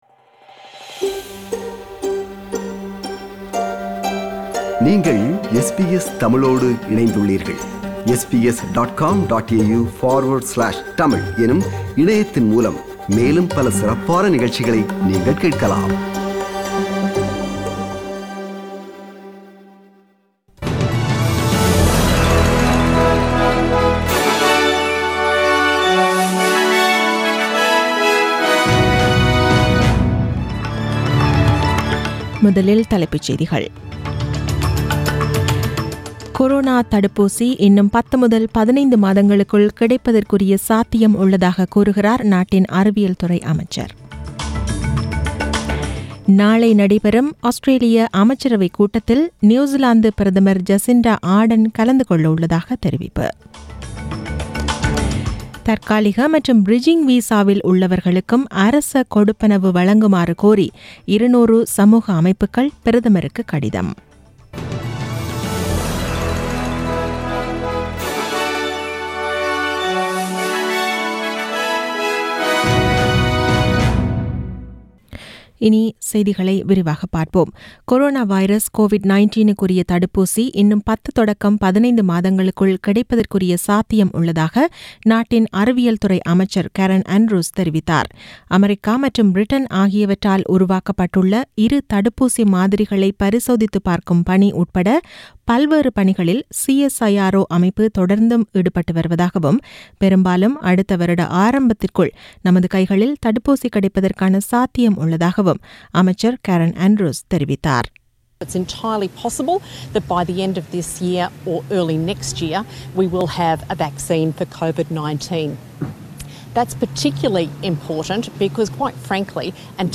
The news bulletin was aired on 4 May 2020 (Monday) at 8pm.